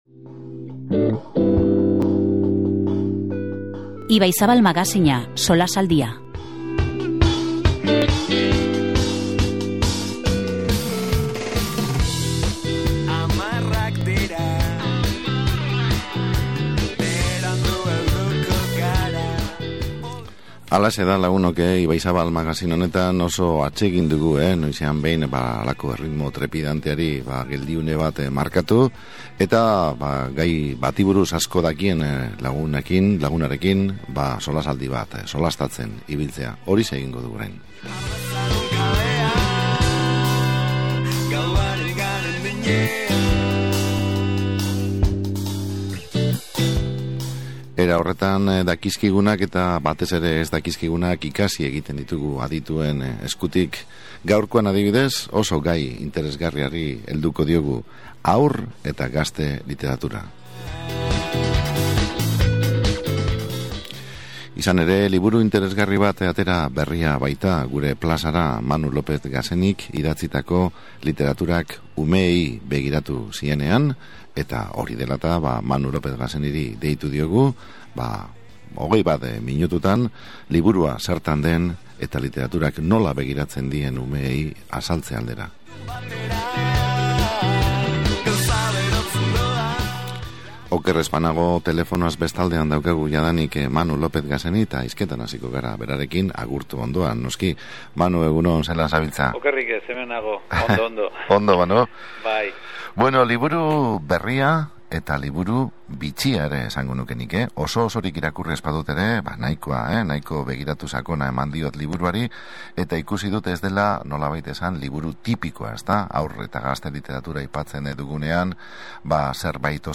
telefonoa bitarteko
SOLASALDIA